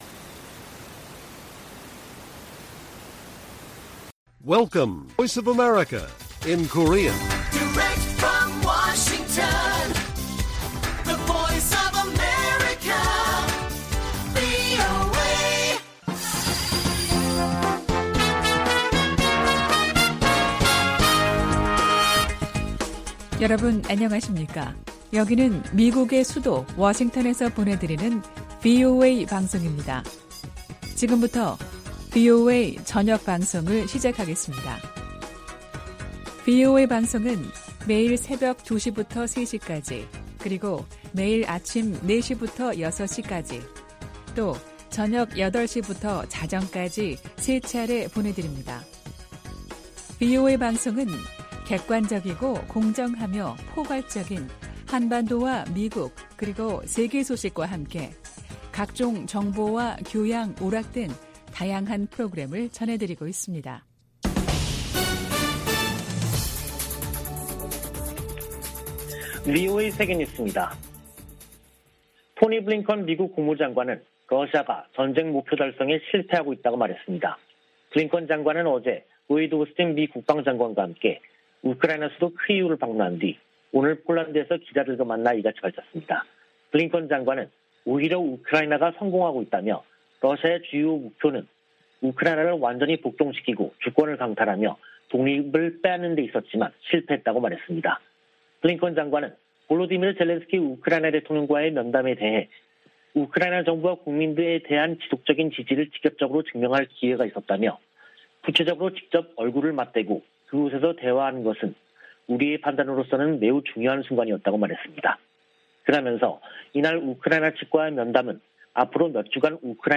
VOA 한국어 간판 뉴스 프로그램 '뉴스 투데이', 2022년 4월 25일 1부 방송입니다.